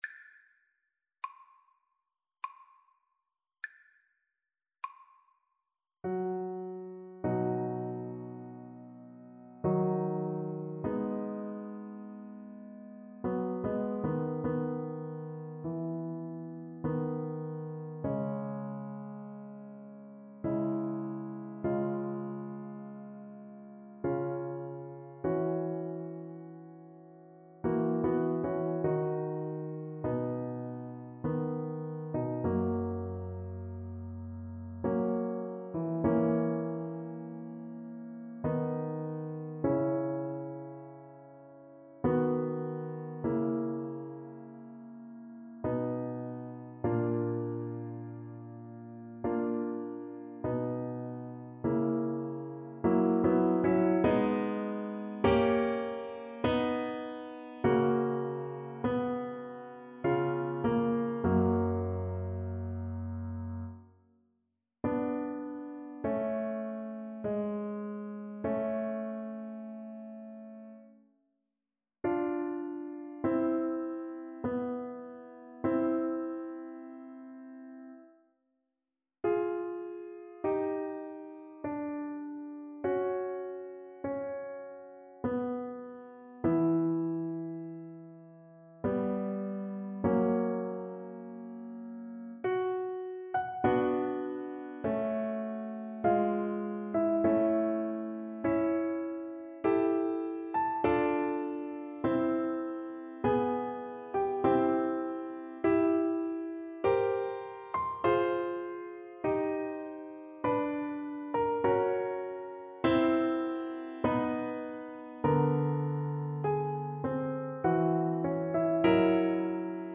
Alto Saxophone
3/4 (View more 3/4 Music)
Andante maestoso = 50
Classical (View more Classical Saxophone Music)